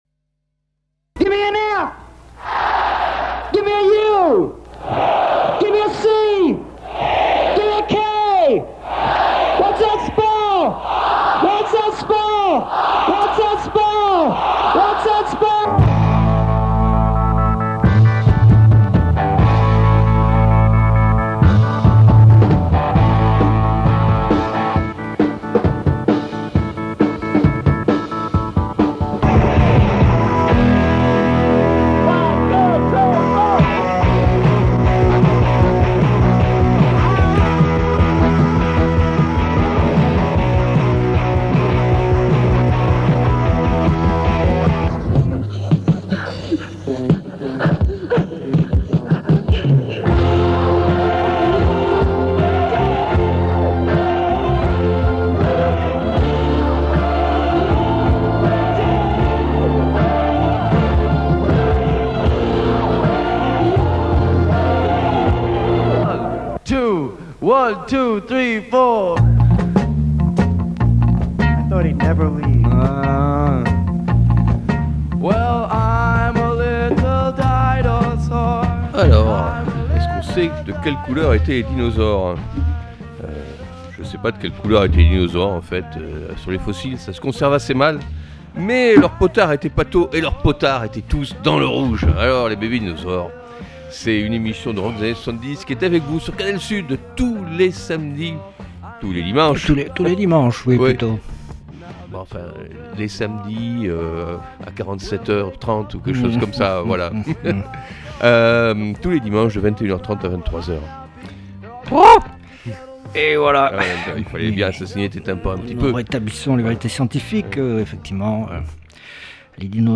Une émission hebdomadaire de rock des années 70